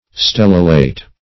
stellulate - definition of stellulate - synonyms, pronunciation, spelling from Free Dictionary Search Result for " stellulate" : The Collaborative International Dictionary of English v.0.48: Stellulate \Stel"lu*late\, a. (Bot.)